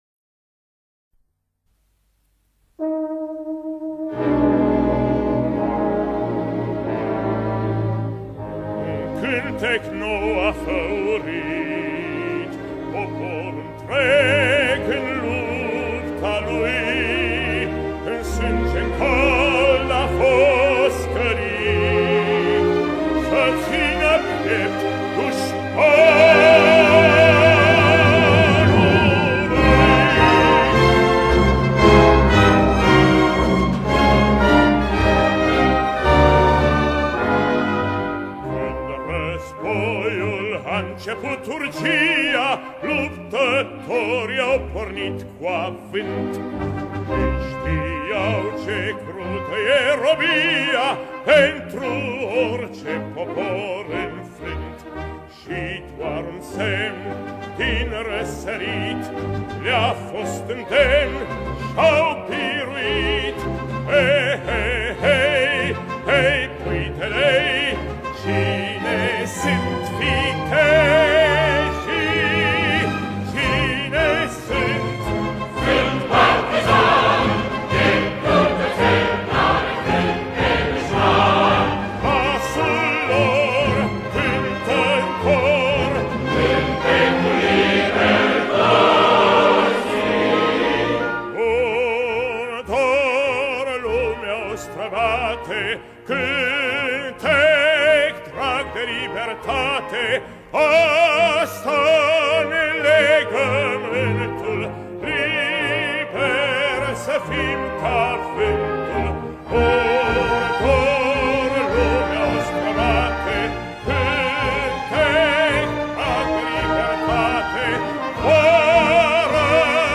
Запись начала 1950-х гг.